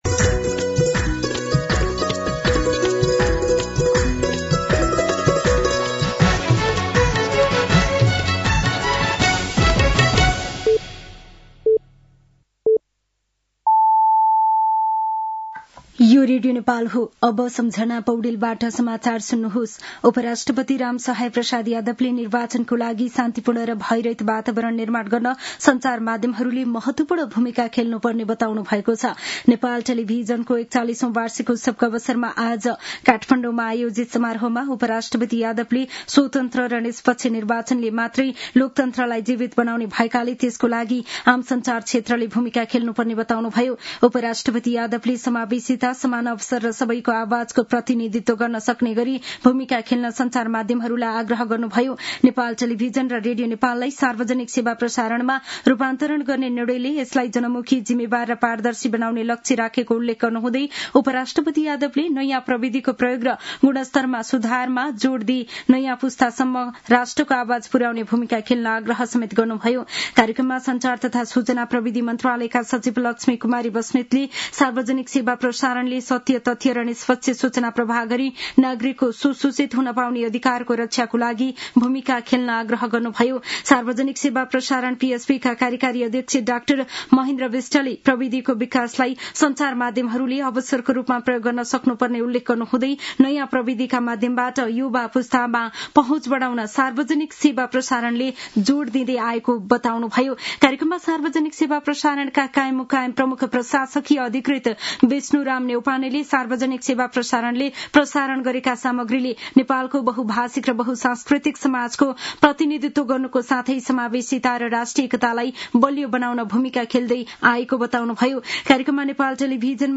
साँझ ५ बजेको नेपाली समाचार : १७ माघ , २०८२
5.-pm-nepali-news-1-12.mp3